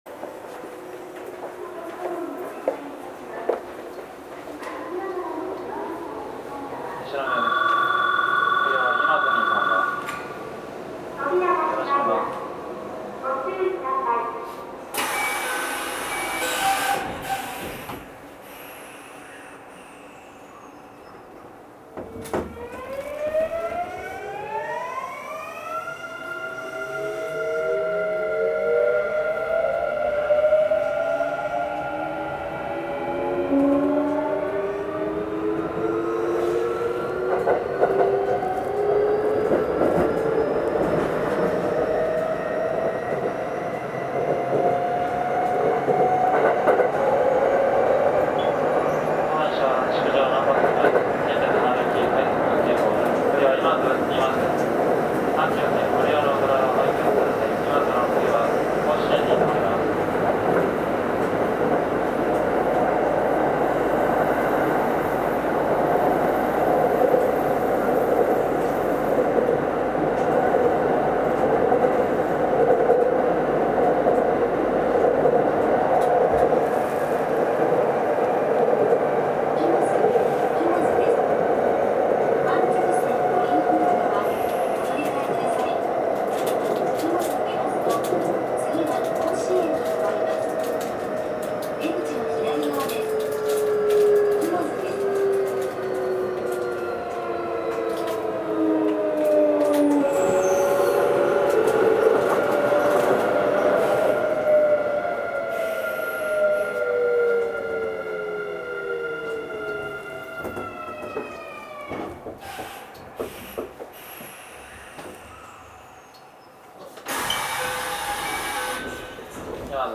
走行機器はIGBT素子によるVVVFインバータ制御で、定格185kWのMB-5085-A形かご形三相誘導電動機を制御します。
走行音
録音区間：西宮～今津(快速急行)(お持ち帰り)